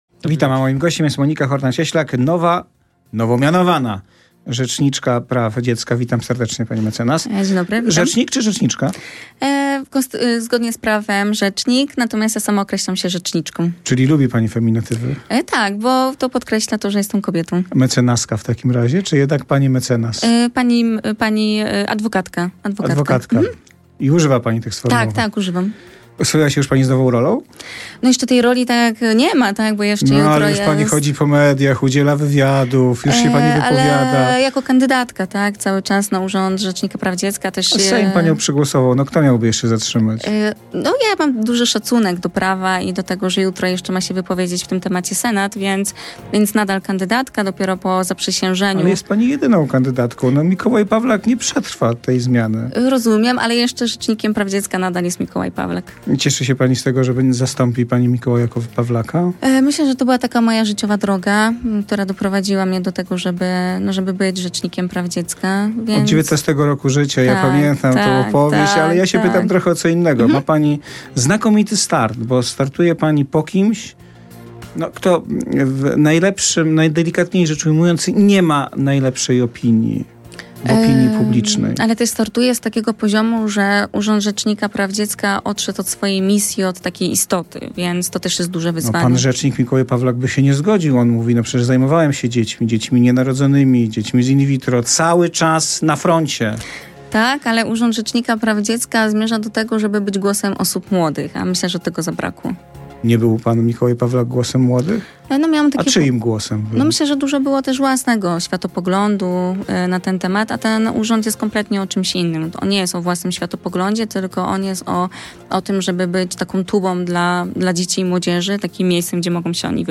Popołudniowa rozmowa w RMF FM to swoiste podsumowanie dnia - komentarz do najważniejszych wiadomości, najbardziej kontrowersyjnych wypowiedzi, spornych decyzji czy dyskusyjnych wyroków. Wywiady w sezonie 2023/2024 prowadzić będą współpracujący z nami dziennikarze